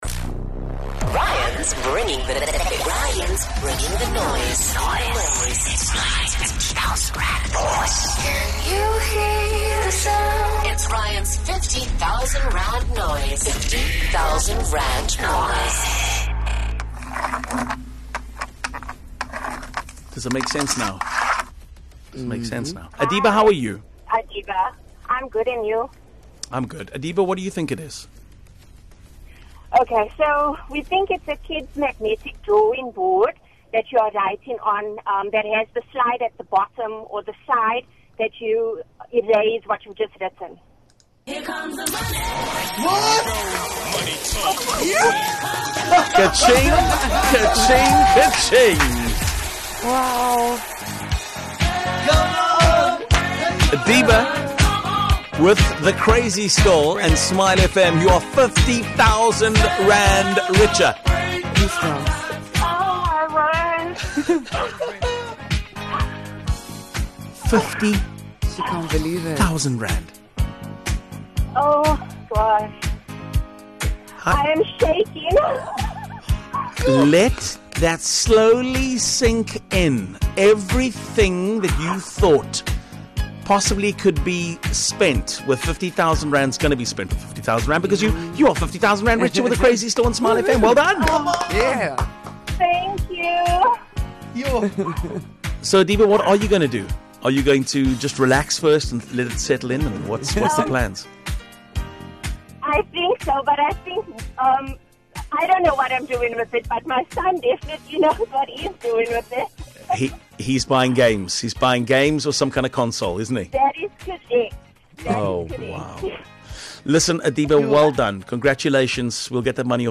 as a kids magnetic drawing board being written on and then erased with the sliding mechanism.